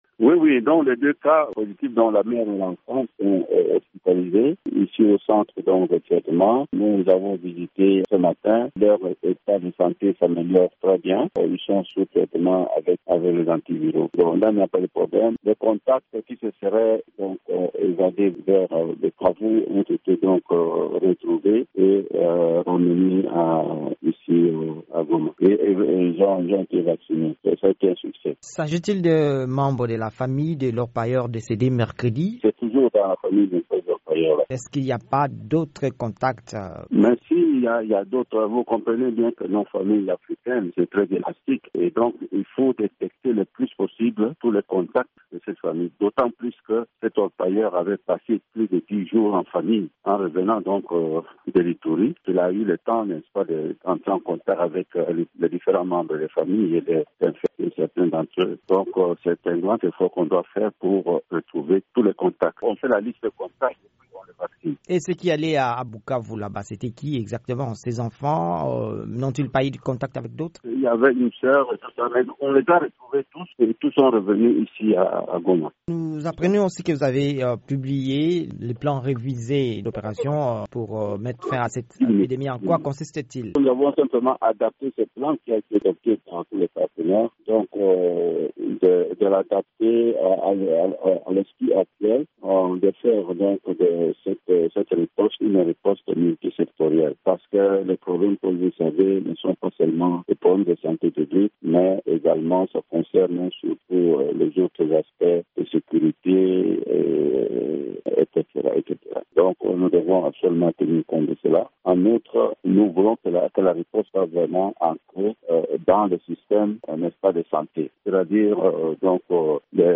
Entretien avec le docteur Jean-Jacques Muyembe sur Ebola